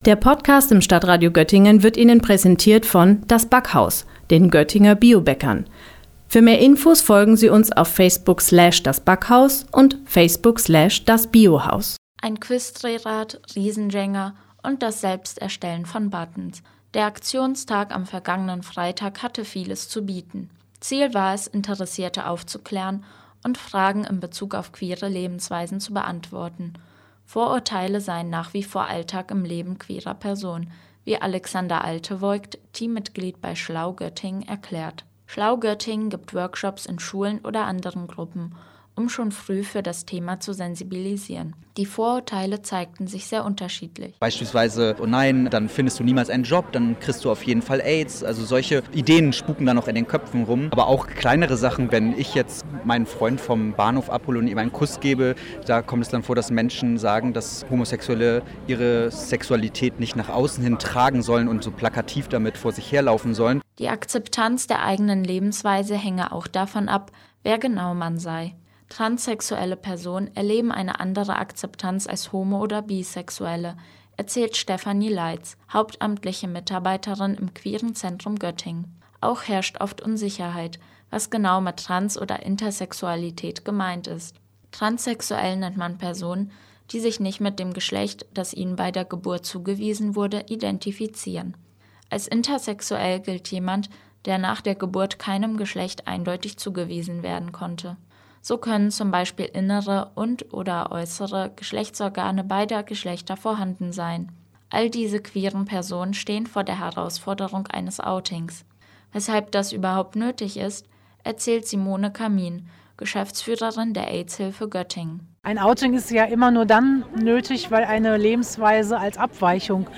Am vergangenen Freitag fand auf dem Gänseliesel ein Aktionstag queerer Gruppen statt. Anlass war der Internationale Tag gegen Homo-, Bi-, Inter*- und Trans*phobie.